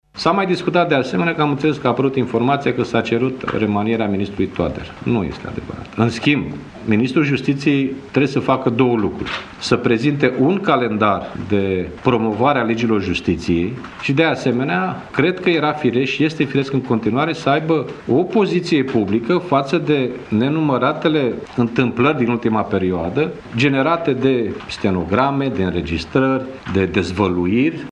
Preşedintele PSD, Liviu Dragnea: